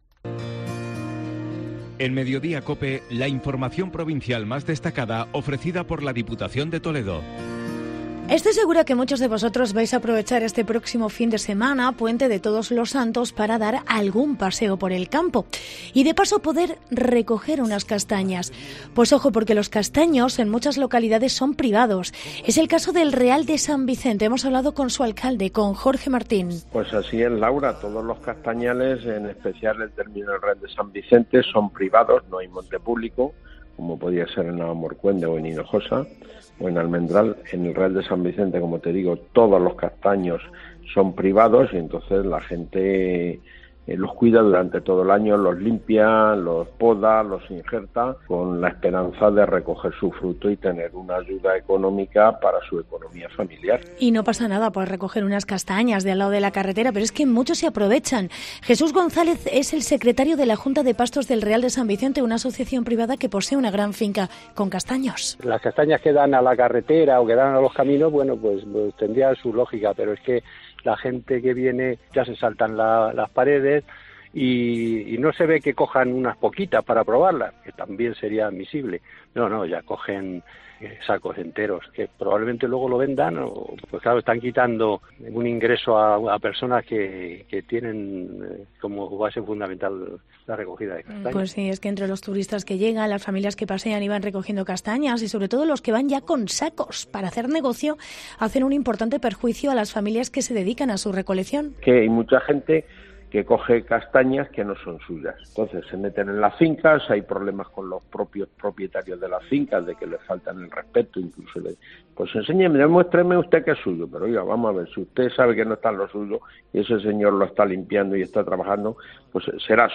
Reportaje recogida ilegal de castañas en el Real de San Vicente